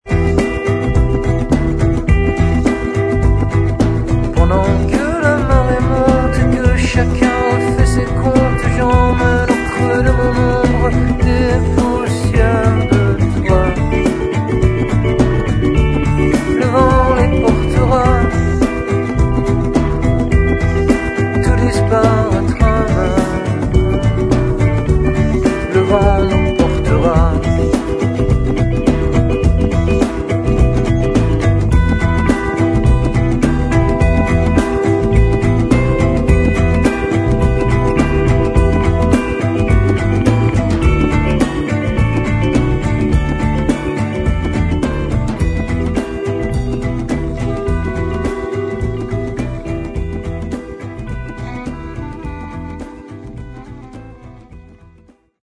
clarinette métal, kalimba
guitare